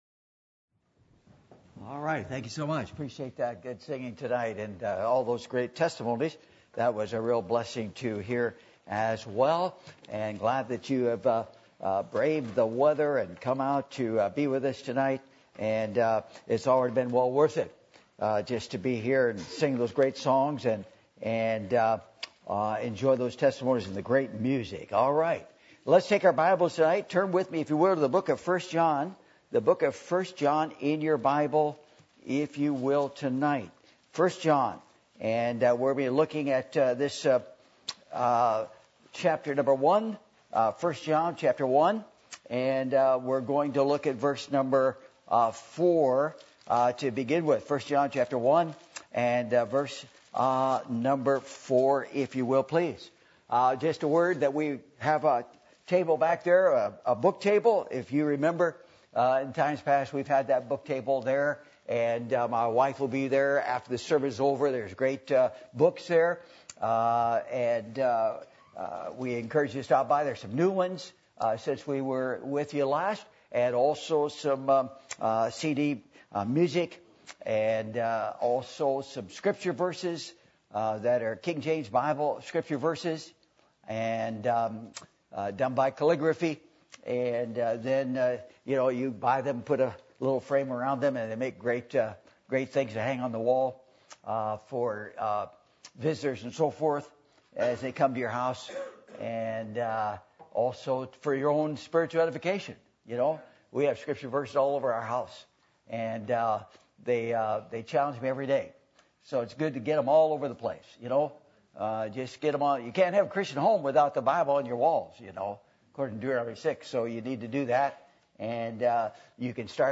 1 John 1:4-5 Service Type: Sunday Evening %todo_render% « If Any One Thirst You Must Be Born Again!